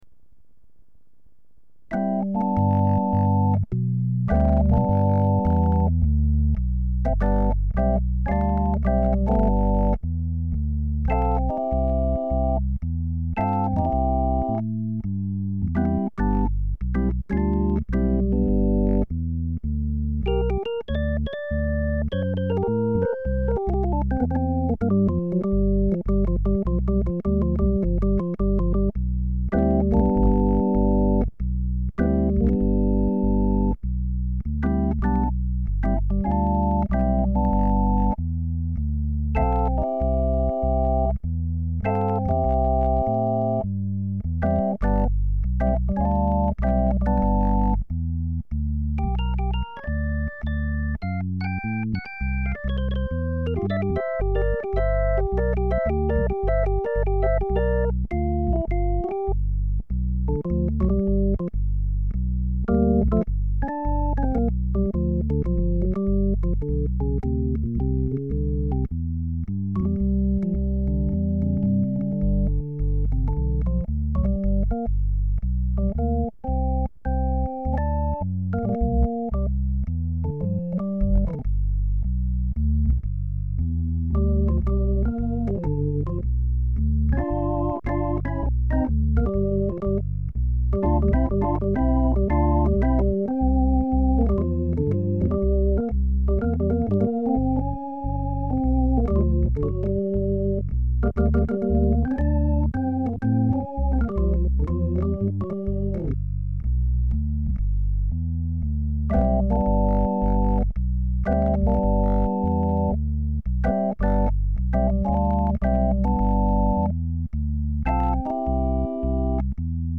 lab Hammond XB 1
Improvvisation.mp3